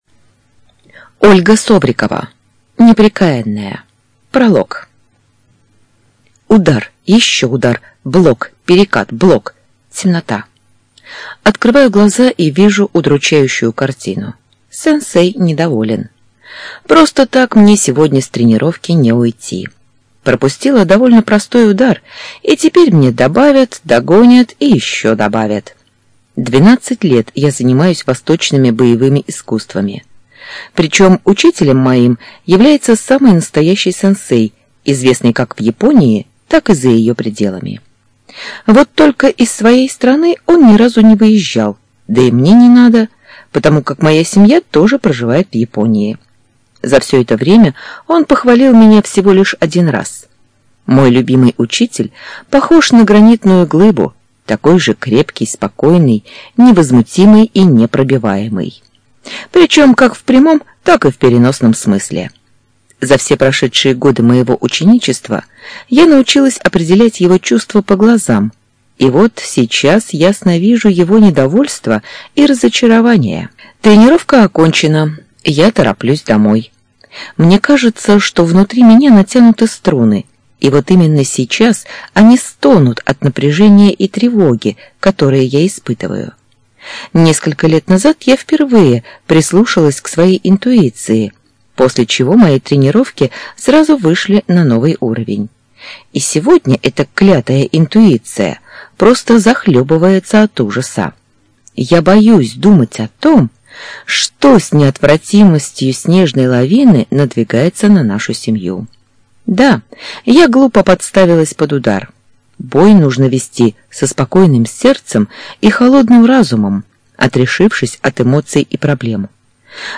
ЖанрЛюбовная проза, Фэнтези